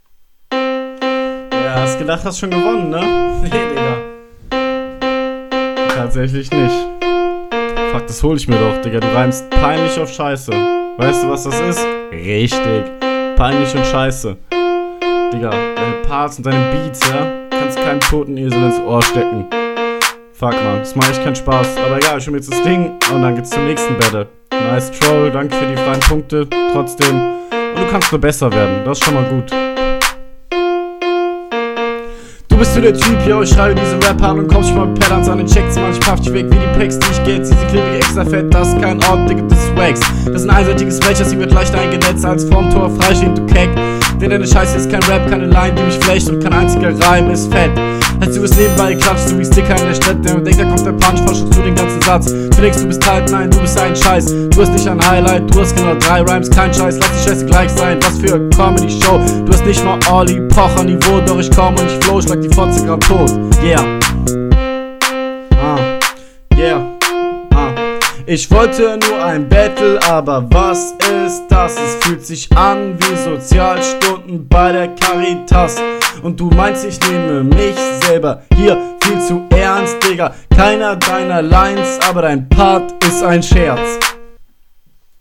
Schön Hops genommen aber Intro unnötig.